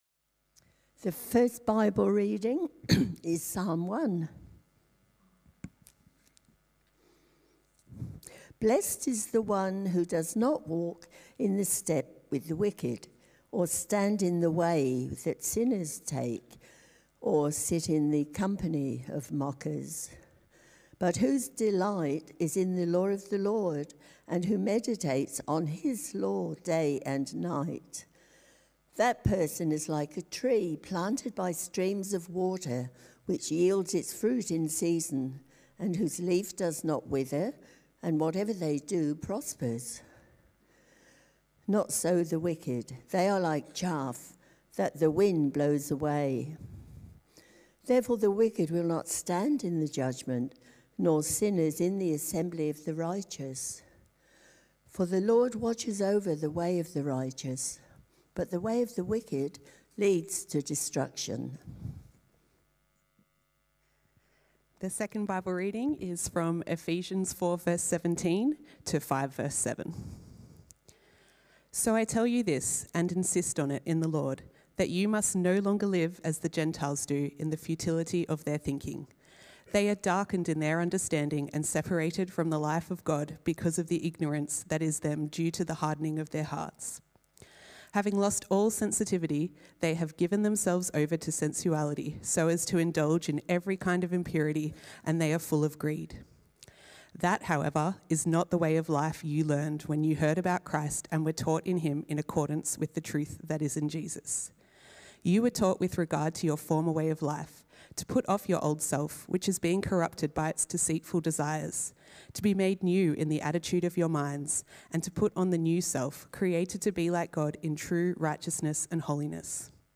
A message from the series "An Invitation to Church."